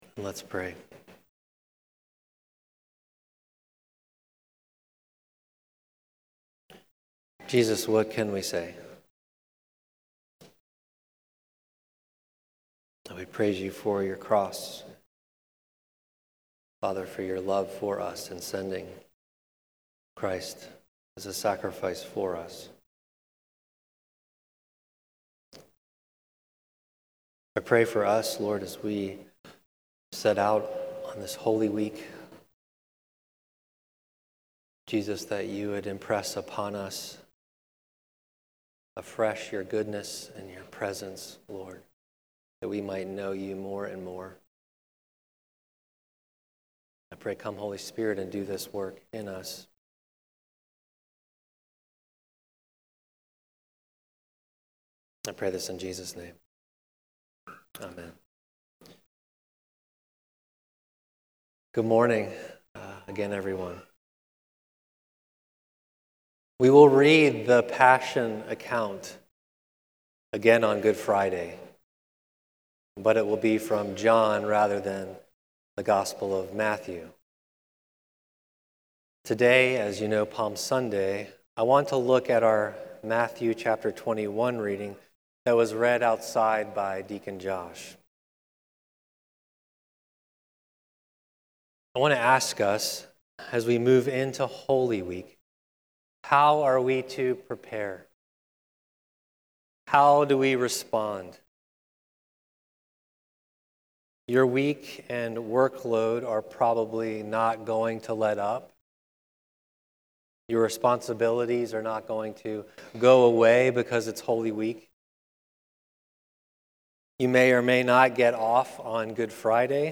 Sermons | Church of the Good Shepherd